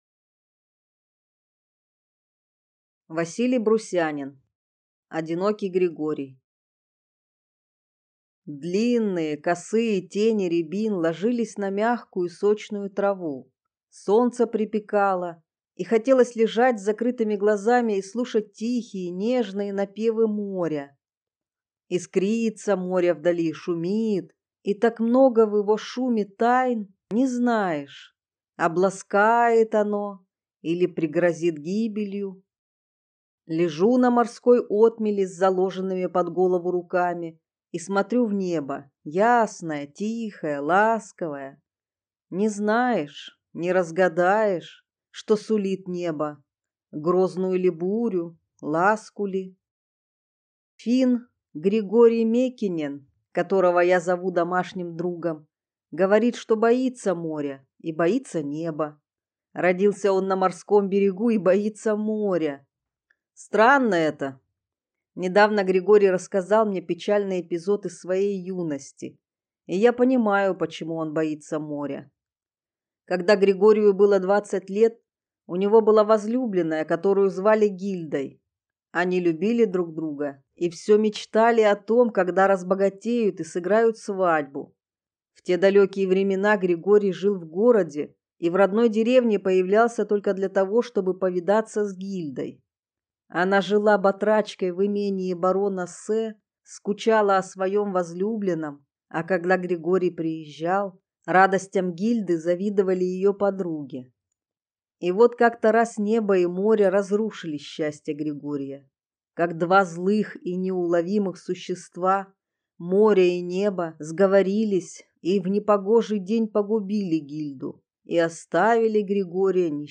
Аудиокнига Одинокий Григорий | Библиотека аудиокниг